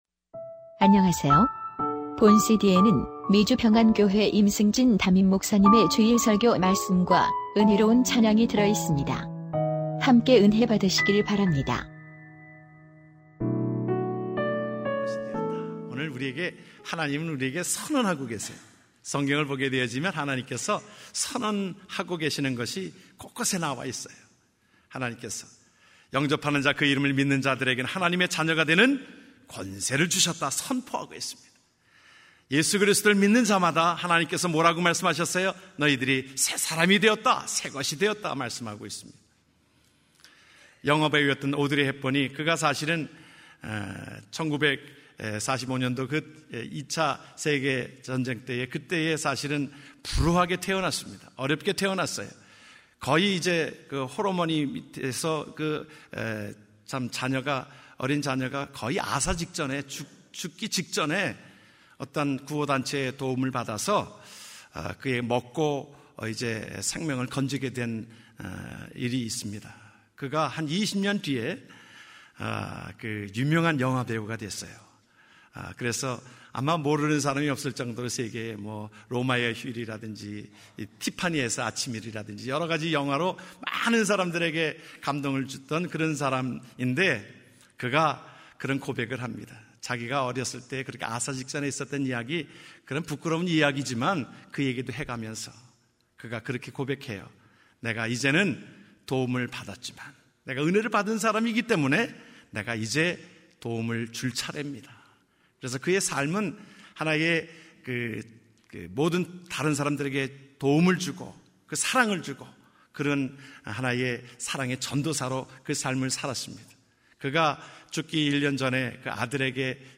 2014년 11월 30일 미주평안교회 주일설교말씀 | 새것이 되었도다(고후5:17-19)